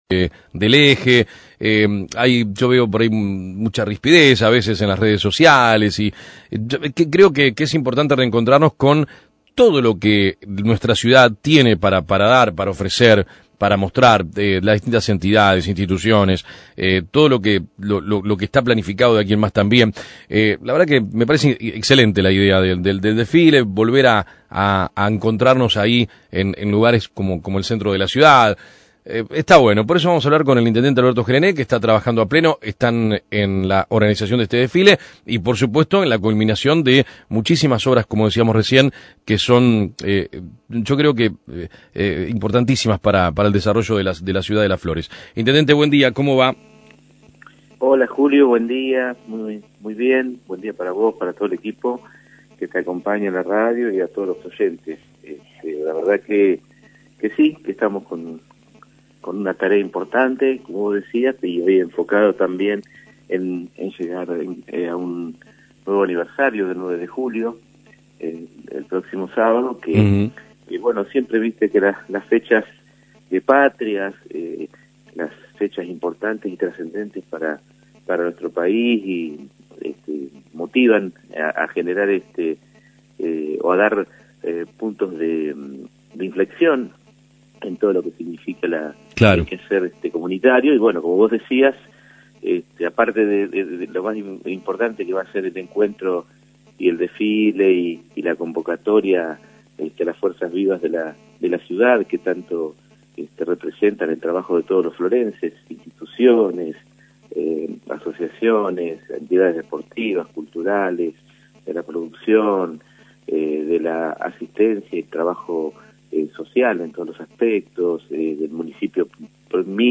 El Intendente Alberto gelené habló en AM 1210 y repasó el trabajo para el crecimiento de la obra pública en la ciudad y proyectos sociales que se están desarrollando: